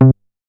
MoogMini 007.WAV